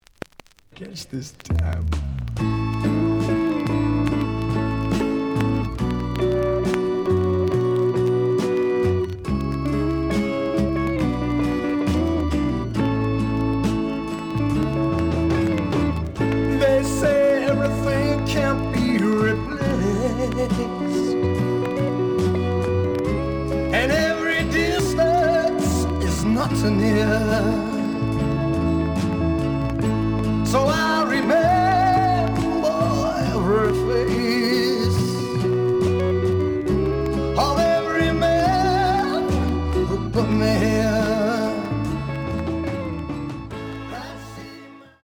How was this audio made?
The audio sample is recorded from the actual item. Some click noise on both sides due to scratches.